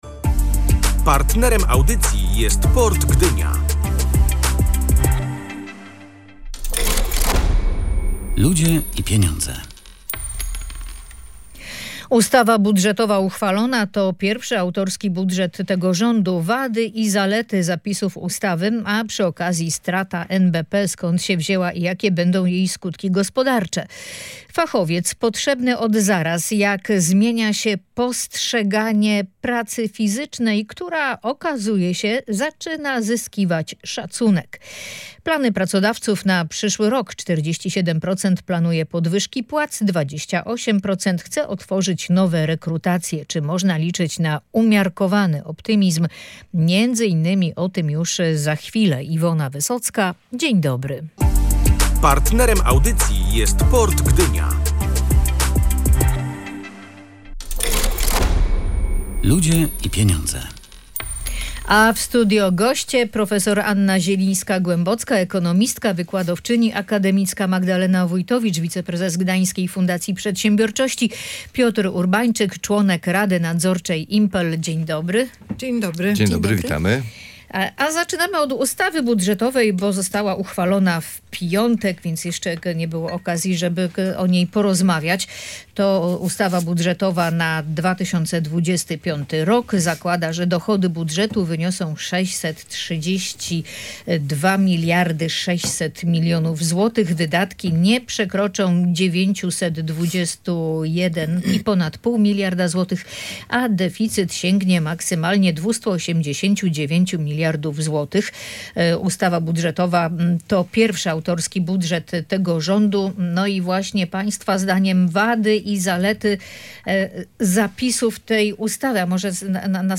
Ludzie i Pieniądze” dyskutowali goście